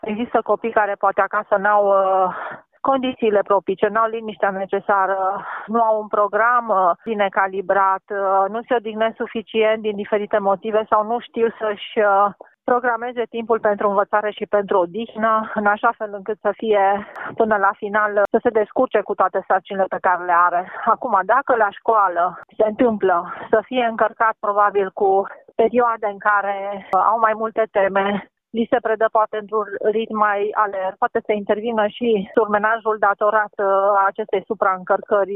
psiholog școlar